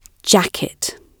jacket-gb.mp3